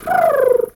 pgs/Assets/Audio/Animal_Impersonations/pigeon_2_emote_11.wav at master
pigeon_2_emote_11.wav